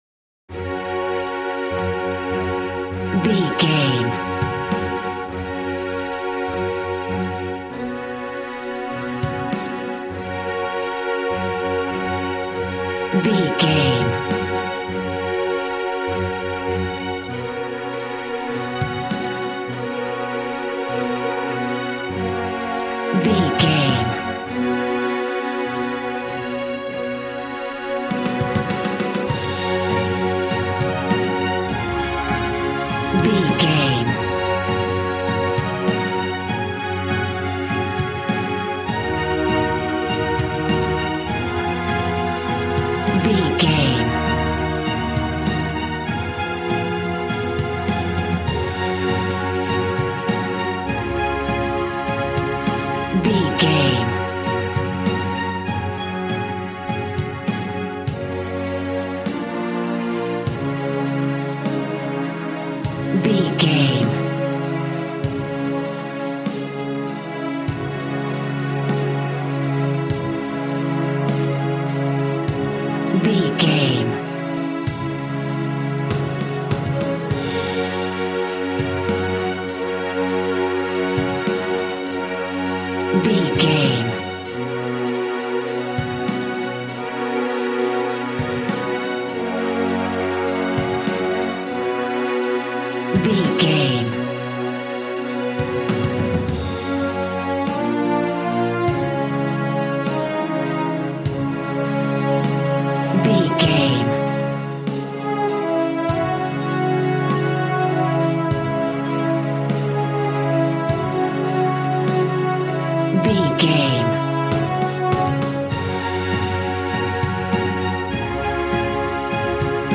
Ionian/Major
G♭
dramatic
strings
violin
brass